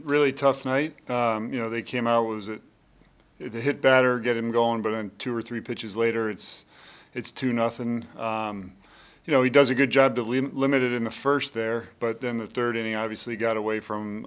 Royals mgr. Matt Quatraro on starter Michael Wacha who had his shortest outing since 2021.